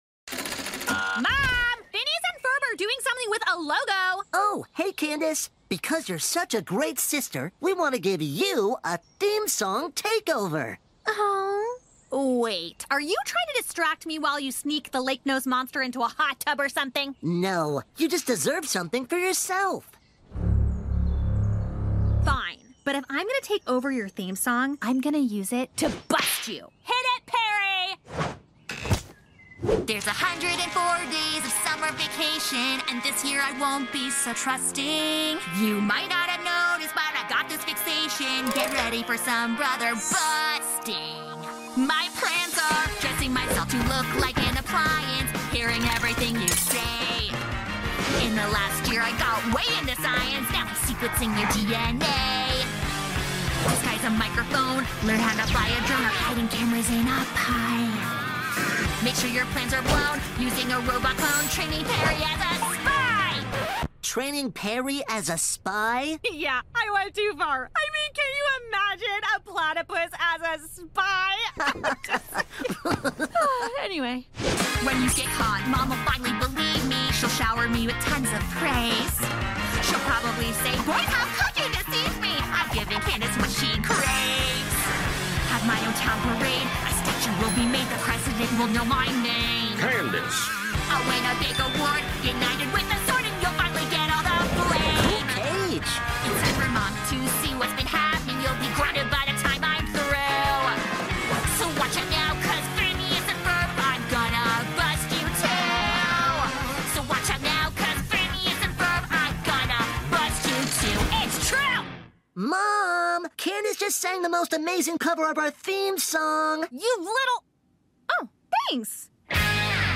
COVER OF OUR THEME SONG